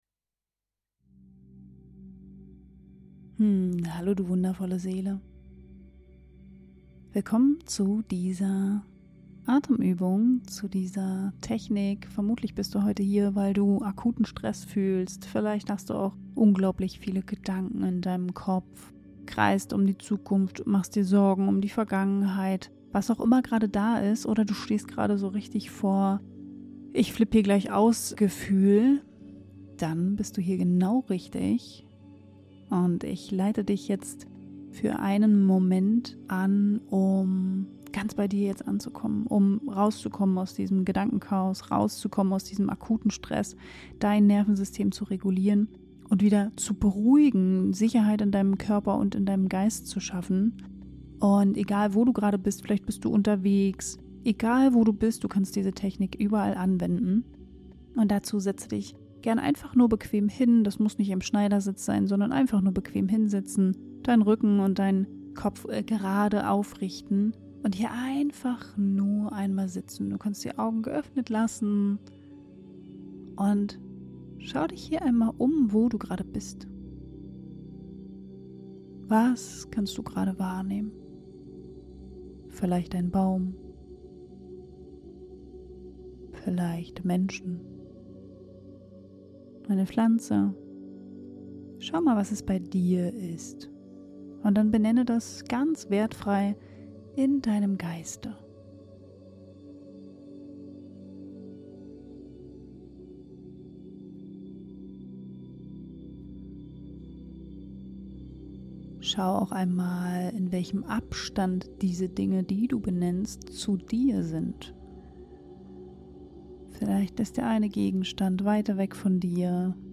Diese geführte Meditation kannst du überall machen, selbst wenn